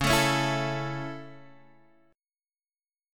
Dbm#5 chord